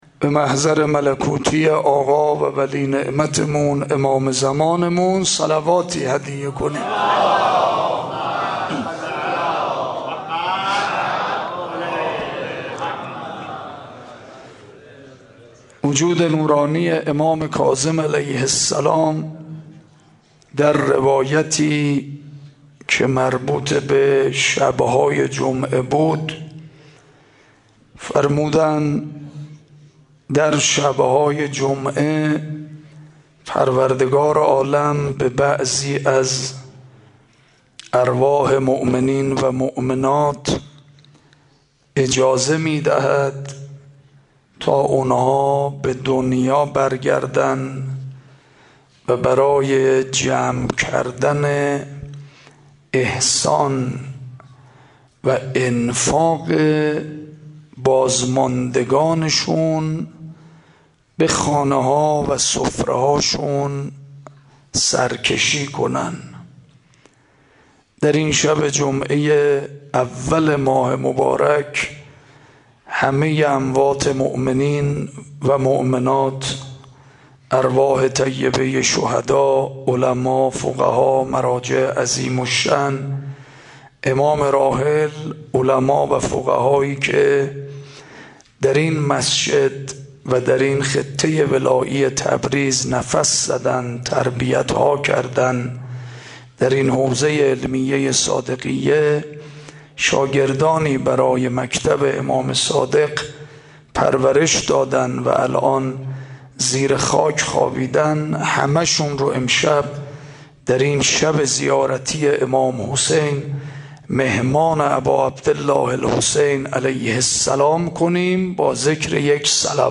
سخنرانی روز سوم ماه مبارک